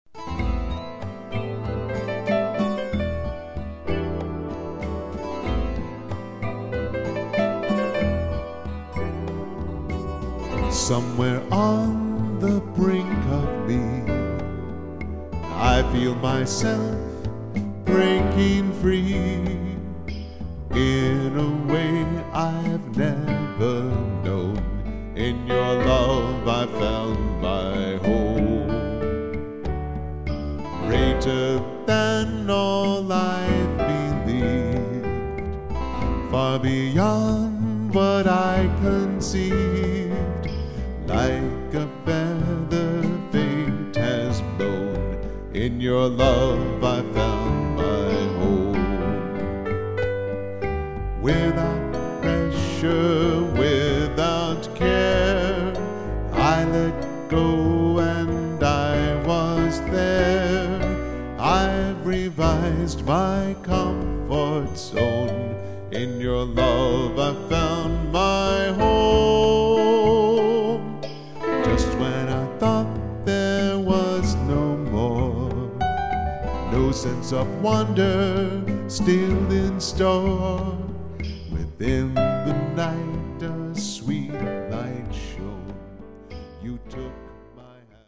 Contemporary Christian music
Keyboard/Vocals